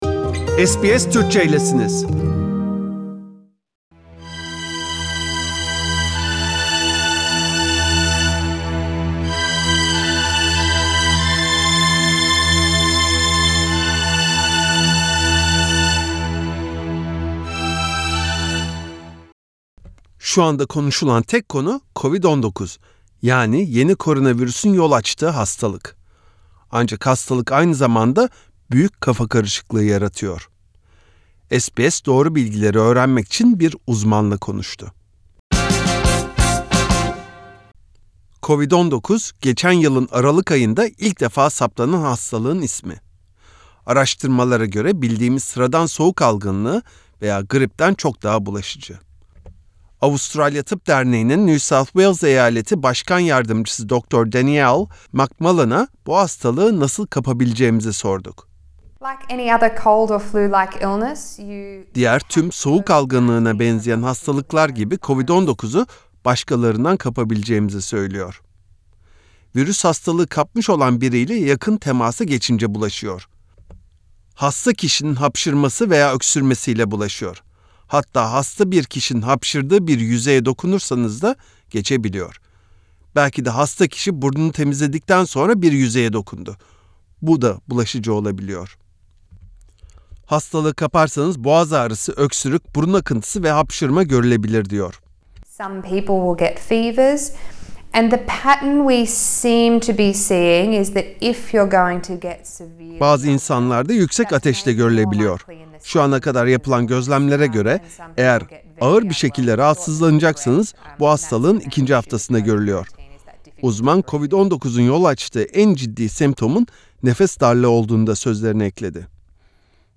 SBS , doğru bilgileri ögrenmek için bir uzmanla konuştu.